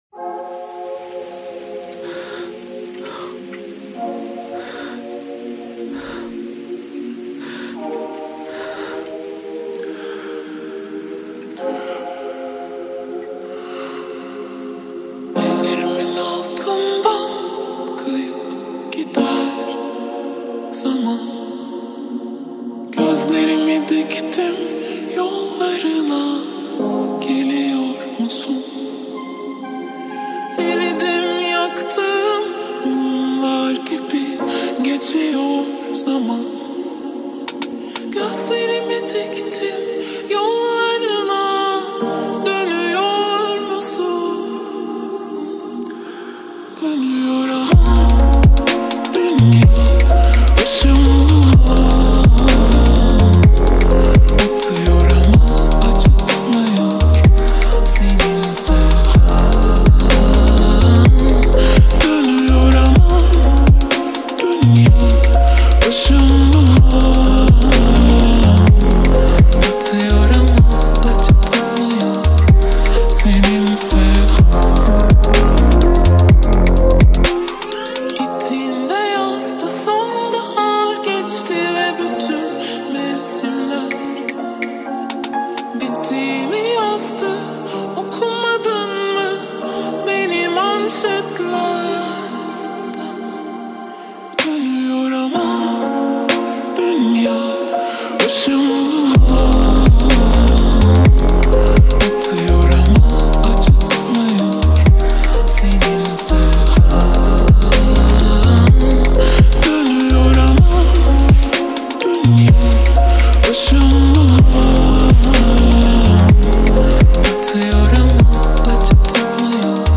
• Жанр: Турецкая музыка